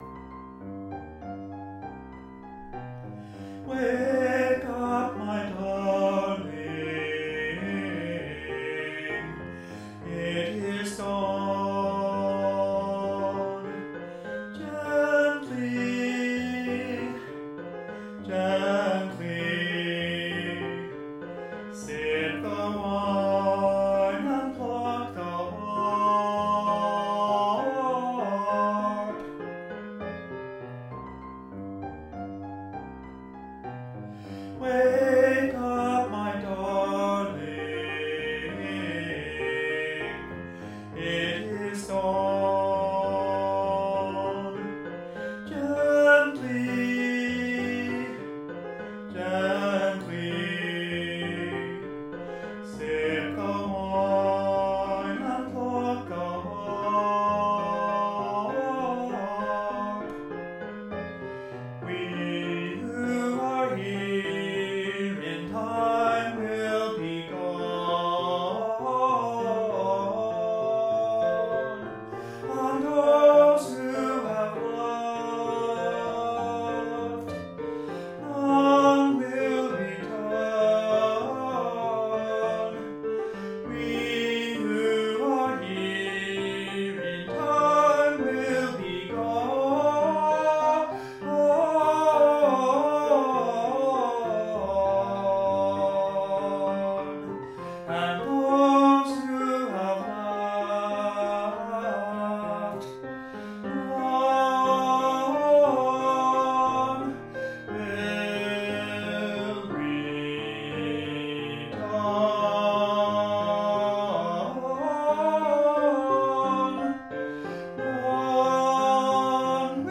tenor
for soprano or tenor and piano